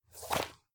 magpouchin.ogg